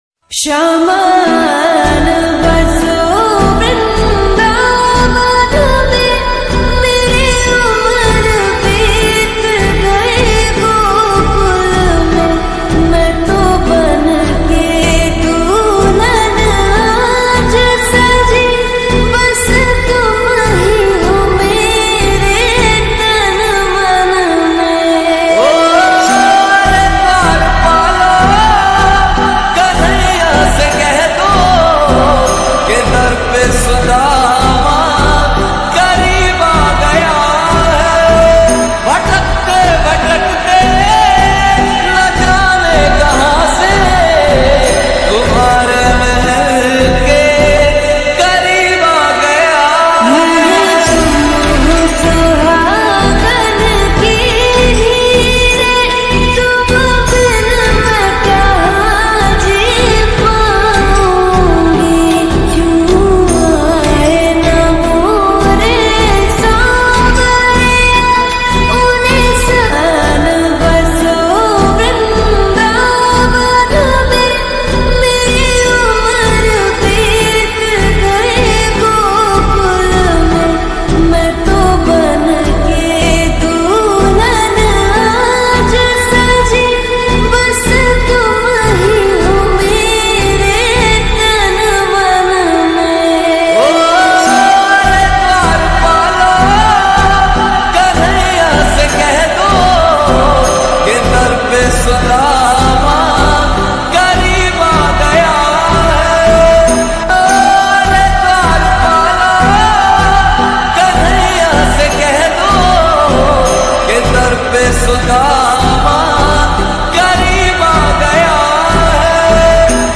• Soft devotional music